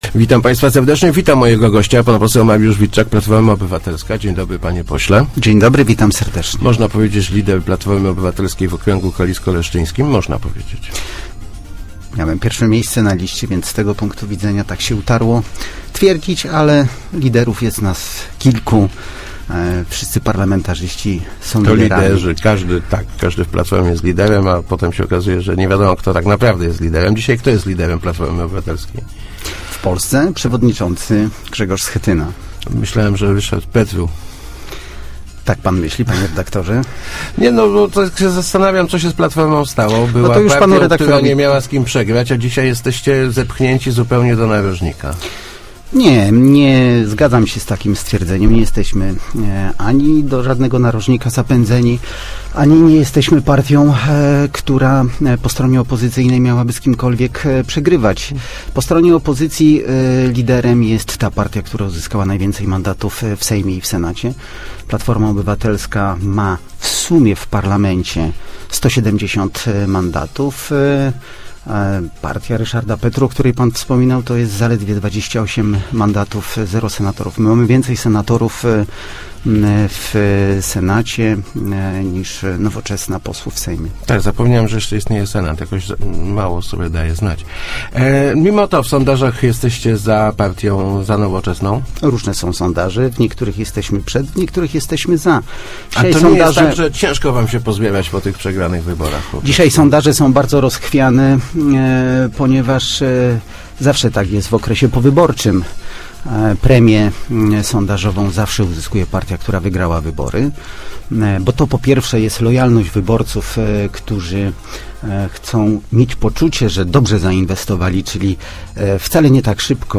Wkrótce zaczniemy odzyskiwa� poparcie w sonda�ach – zapewnia� w Rozmowach Elki pose� PO Mariusz Witczak.